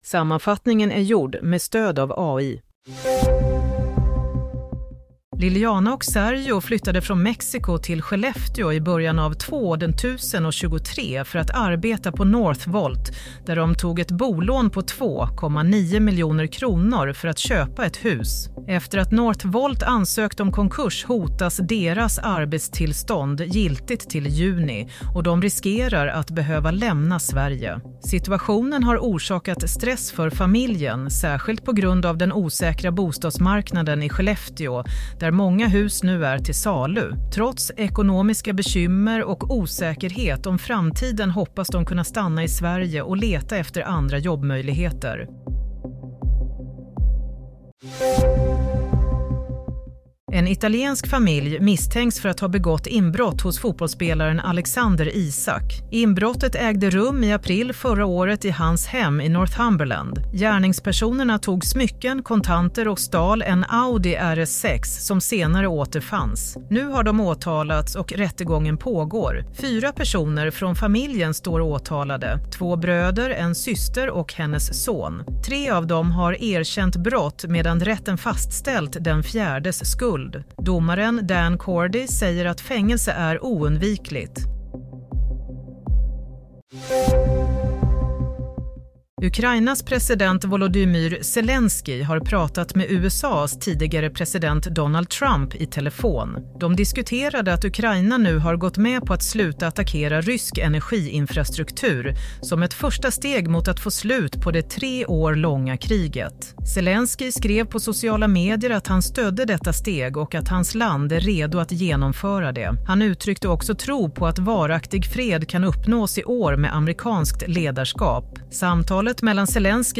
Nyhetssammanfattning - 19 mars 22.00